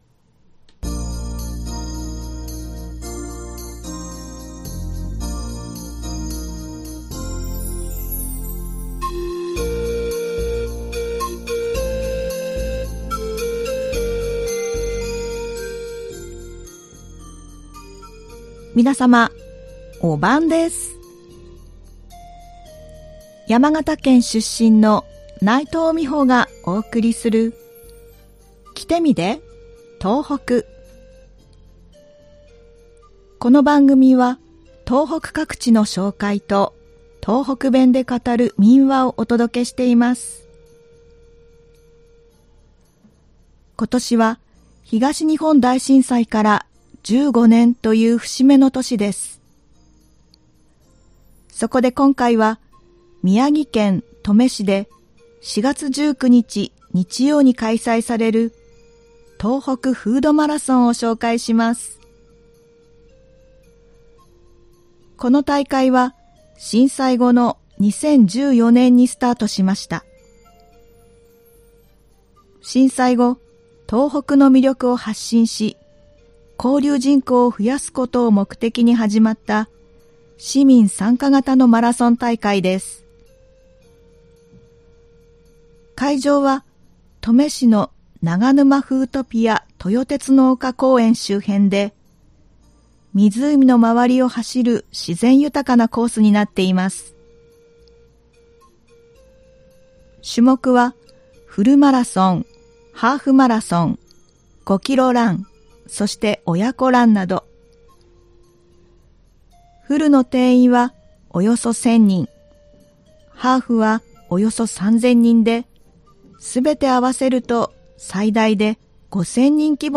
ではここから、東北弁で語る民話をお送りします。今回は宮城県で語られていた民話「親捨て山」です。